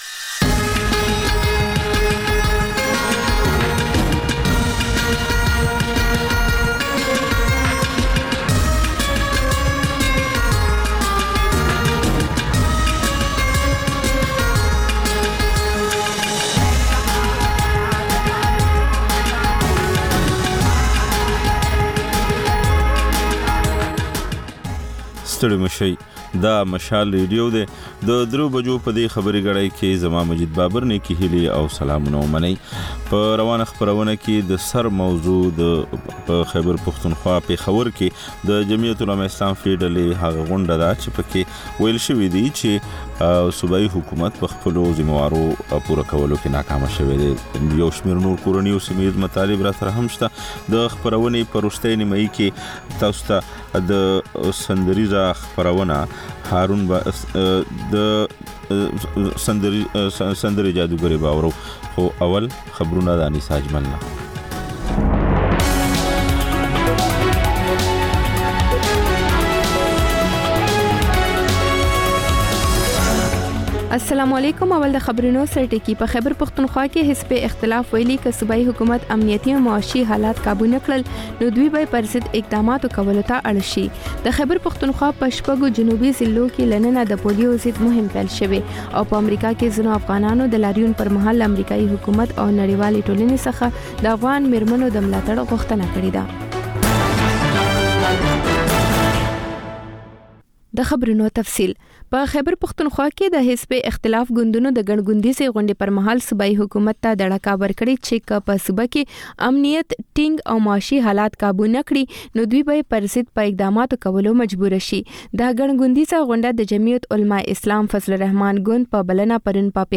د مشال راډیو درېیمه یو ساعته ماسپښینۍ خپرونه. تر خبرونو وروسته، رپورټونه، شننې، او رسنیو ته کتنې خپرېږي.